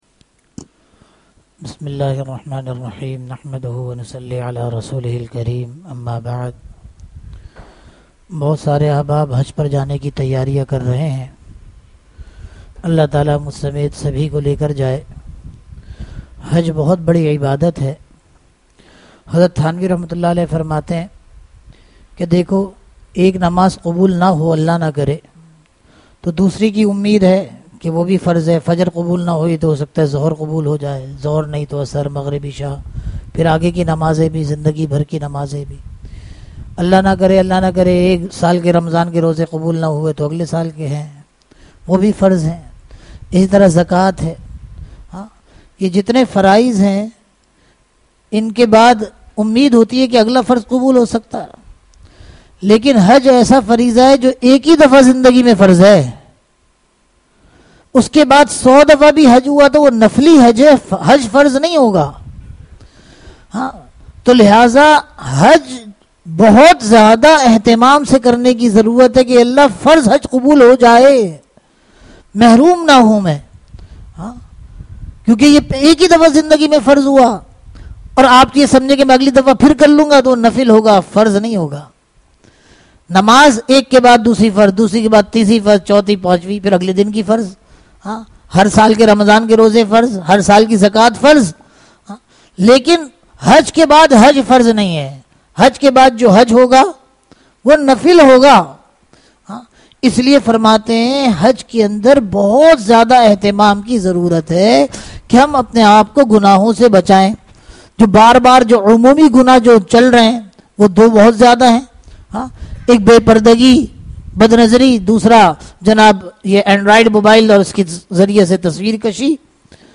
Bayanat
Bayanat Bayanat (Jumma Aur Itwar) Bad Jummah 26th May 2023 Category Bayanat Sub-Category Bayanat (Jumma Aur Itwar) Date 26th May 2023 Size 5.08 MB Tags: Download Source 1 Download Source 2 Share on WhatsApp